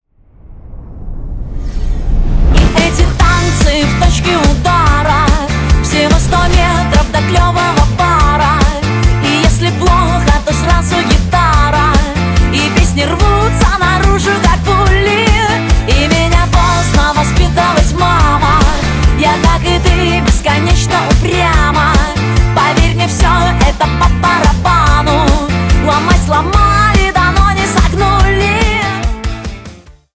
Рок Металл # Танцевальные
весёлые